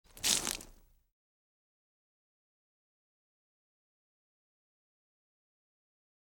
Звуки чашки
Звук пролитого кофе на человека